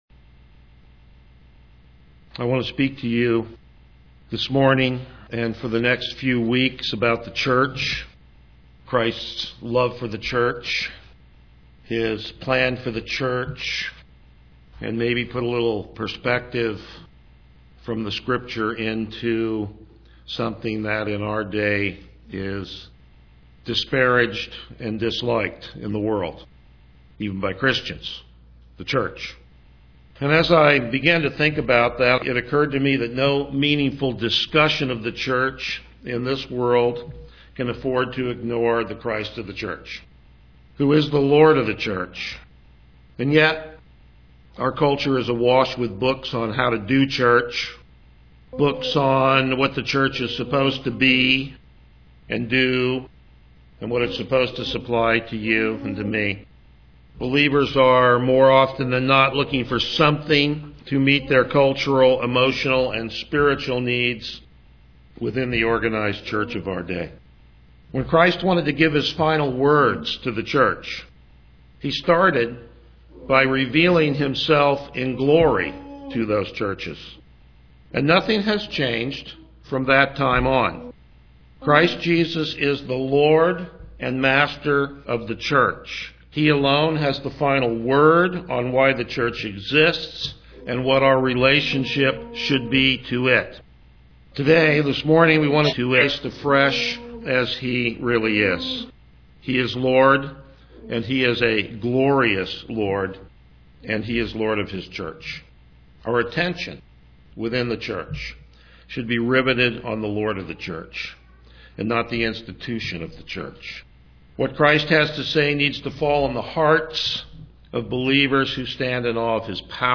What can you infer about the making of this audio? Passage: Revelation 1:1-20 Service Type: Morning Worship